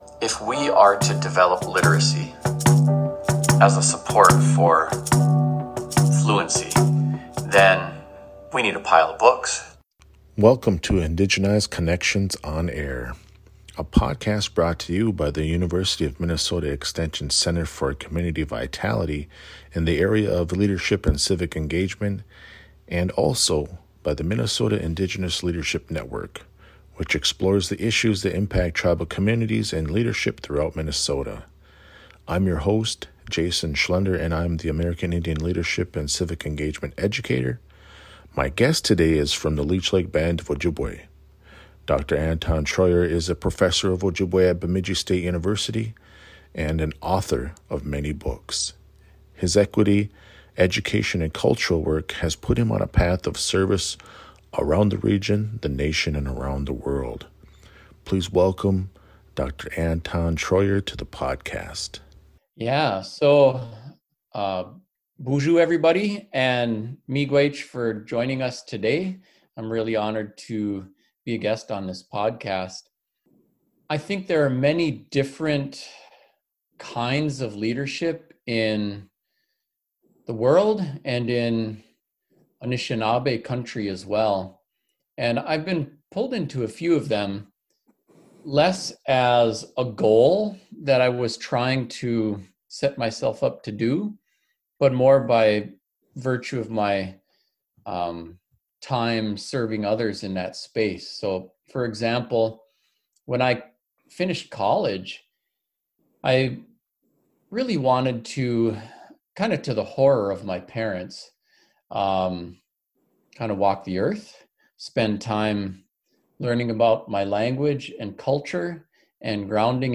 Join us for a conversation with Dr. Anton Treuer as he shares stories about resilience and how it influenced his leadership journey, his relationship with tribal elders, his take on overall wellness, and his COVID-19 response. Anton shares the latest strategies on Indigenous language revitalization initiatives and many more topics on the latest episode of Indigenized Connections On-Air.